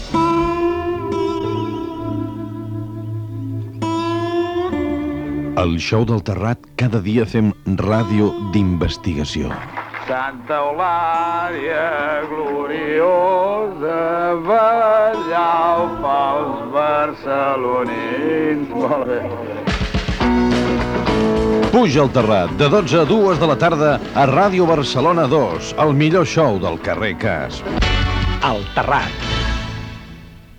Promoció del programa en la qual canta l'alcalde de Barcelona Pasqual Maragall.